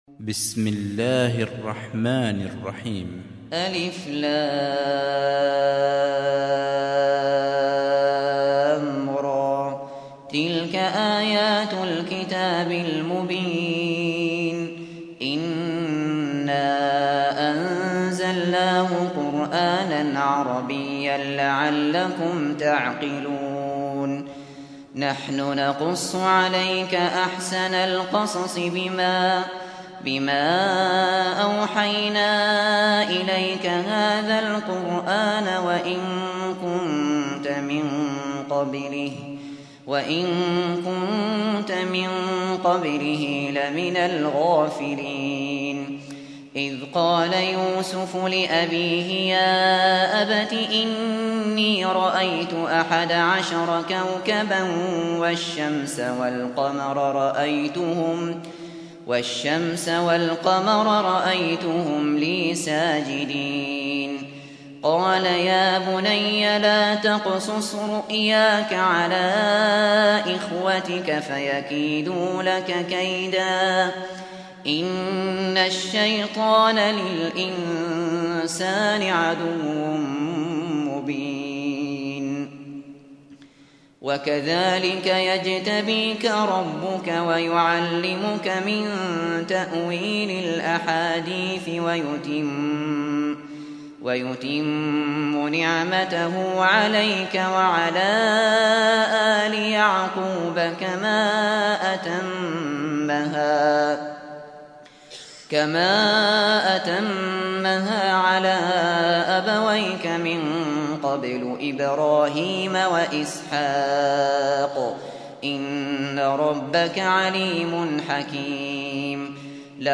سُورَةُ يُوسُفَ بصوت الشيخ ابو بكر الشاطري